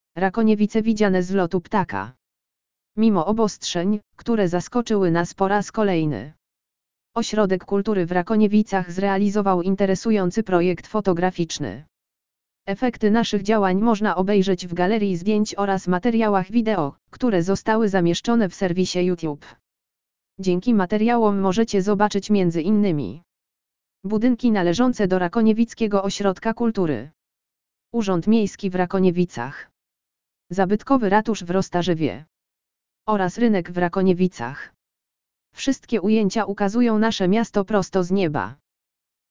lektor_audio_rakoniewice_widziane_z_lotu_ptaka.mp3